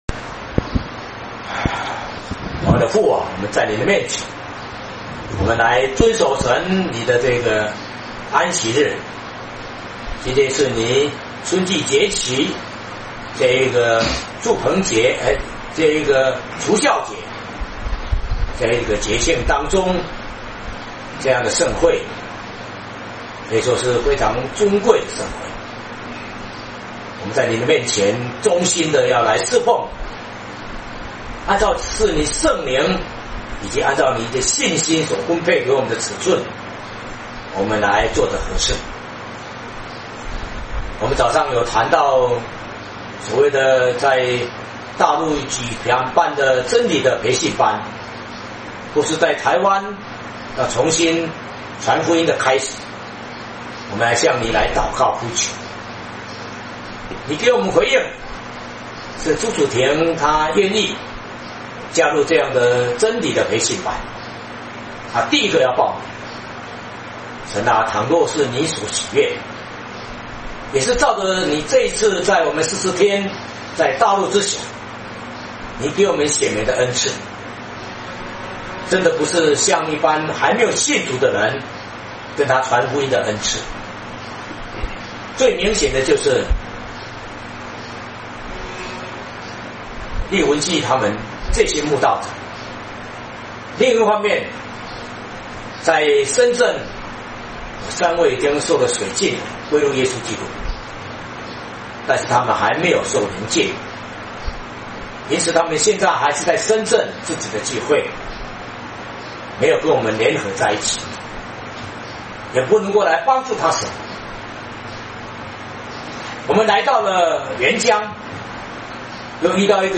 詩歌頌讚